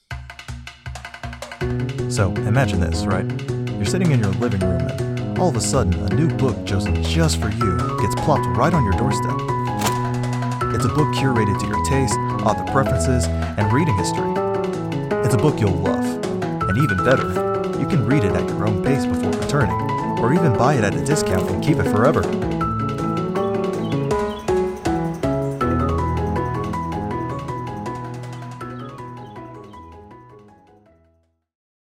Subscription Service - Friendly